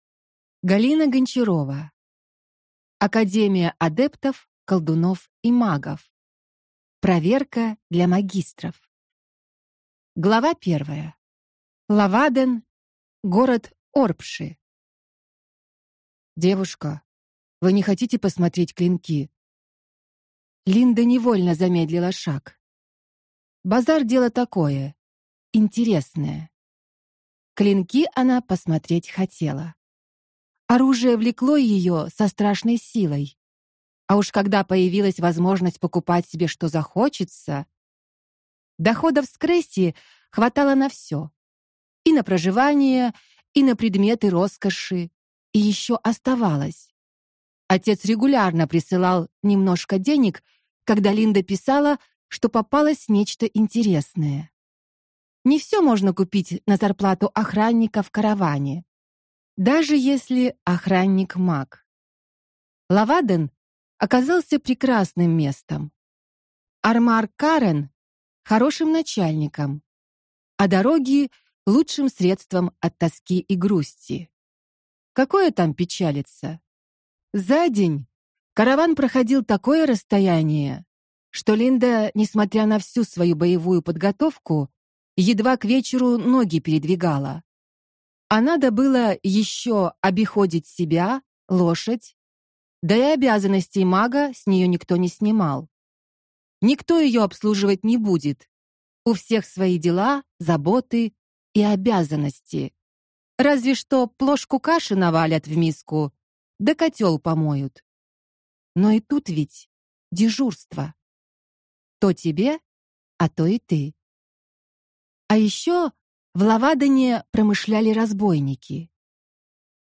Аудиокнига Академия адептов, колдунов и магов. Проверка для магистров | Библиотека аудиокниг